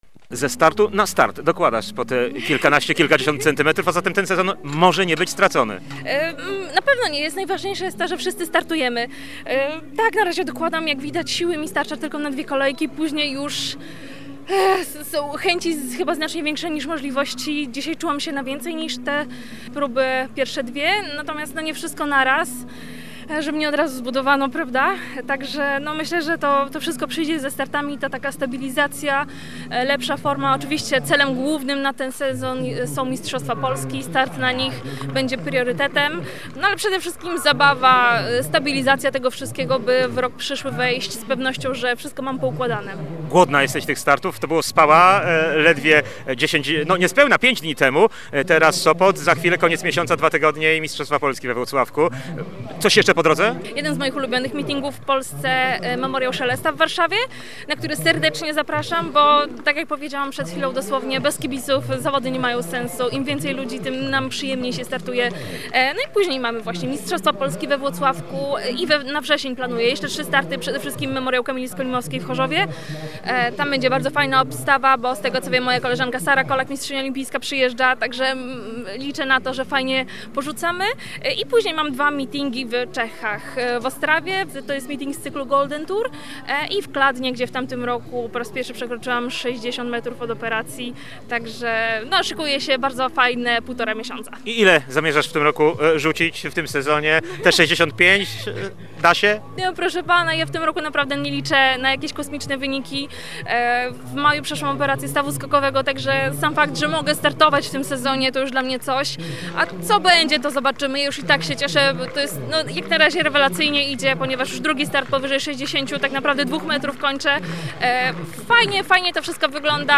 rozmowy
po 23. Lekkoatletycznym Grand Prix Sopotu